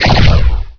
bolt_fire.wav